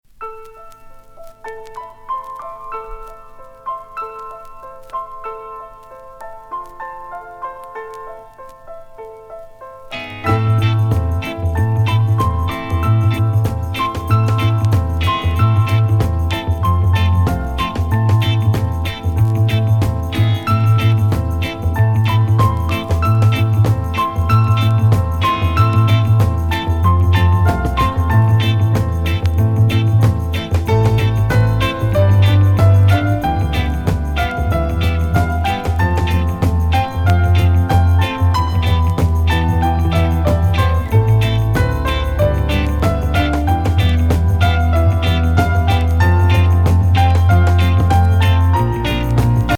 フルート